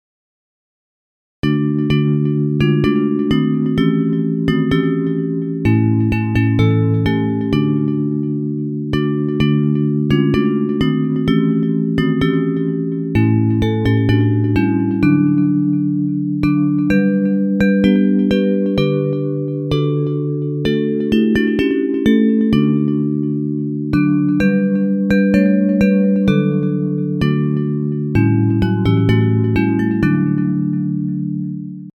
Hymns of praise